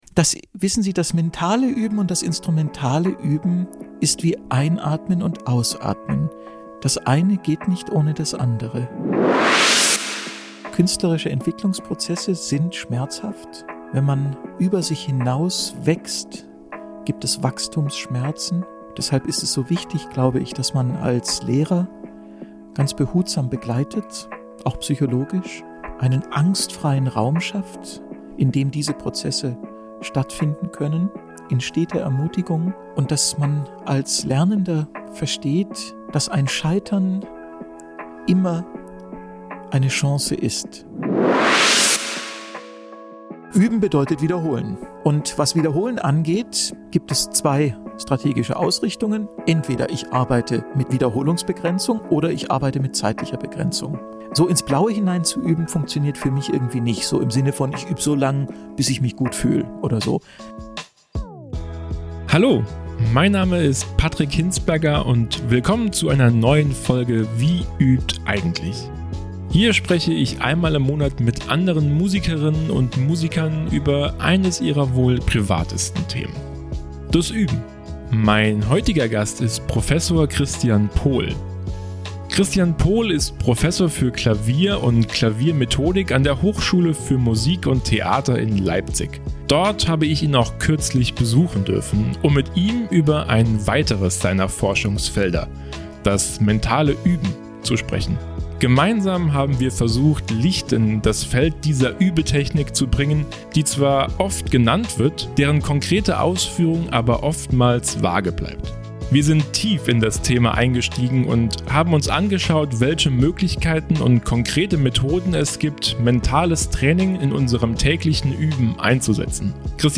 Dort habe ich ihn kürzlich auch besuchen dürfen, um mit ihm über ein weiteres seiner Forschungsfelder - das mentale Üben - zu sprechen. Gemeinsam haben wir versucht Licht in das Feld dieser Übe-Technik zu bringen, die zwar oft genannt wird - deren konkrete Ausführung aber oftmals vage bleibt.